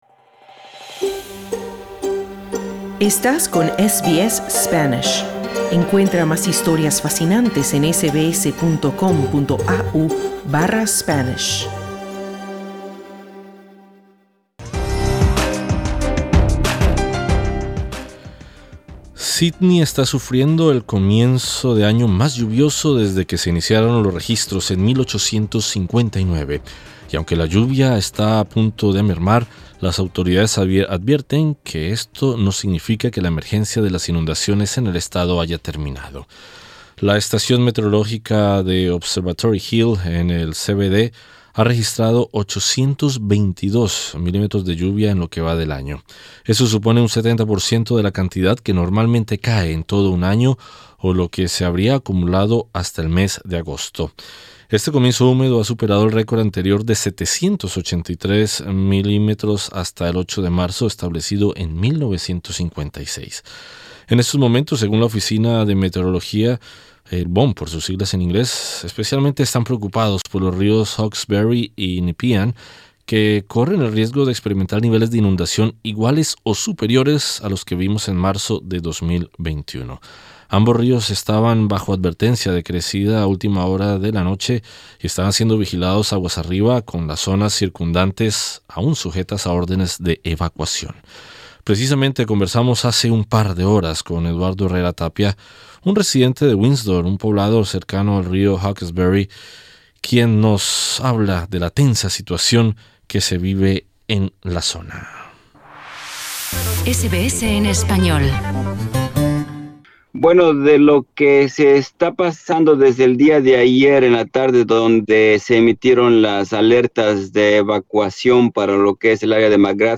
En entrevista con SBS Spanish